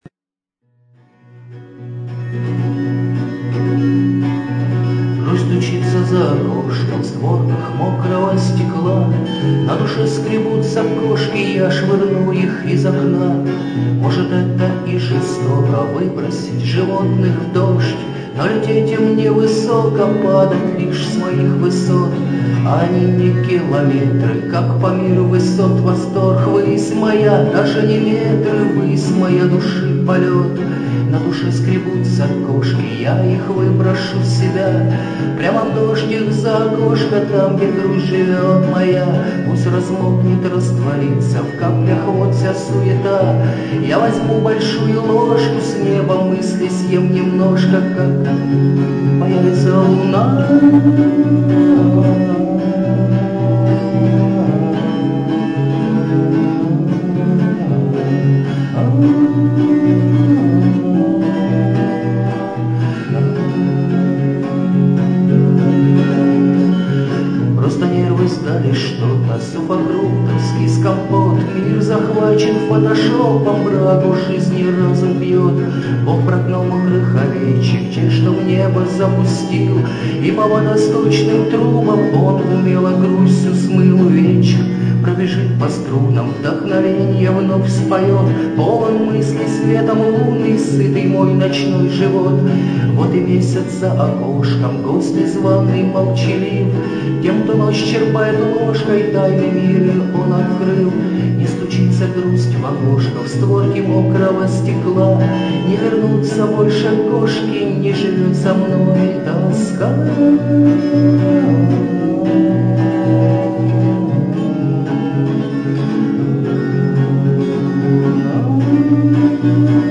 авторское исполнение под гитару